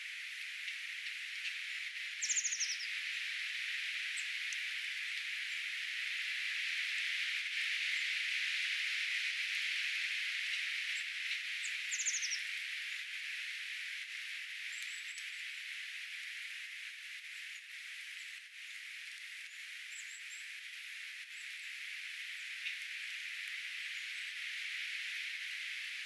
tsak-ääniä enempikin
mika_laji_tsak-aania_jollain_linturuokinnalla.mp3